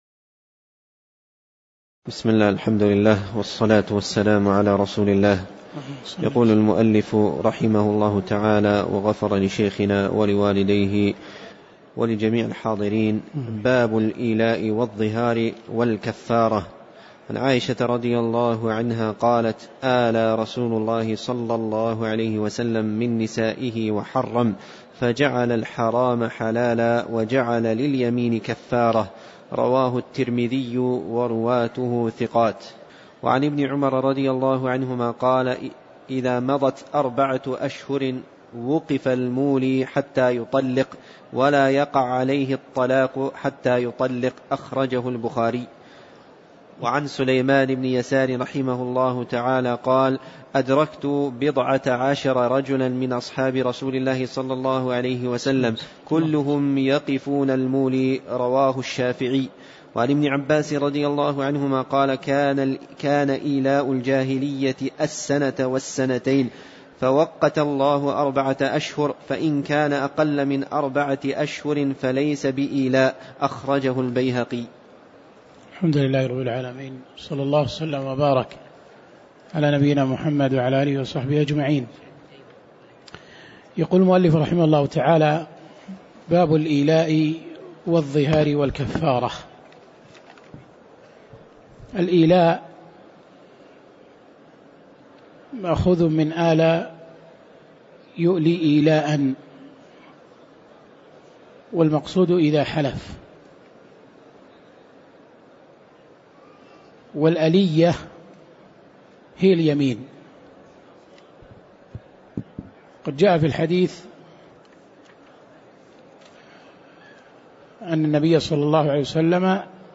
تاريخ النشر ٢٢ جمادى الآخرة ١٤٣٨ هـ المكان: المسجد النبوي الشيخ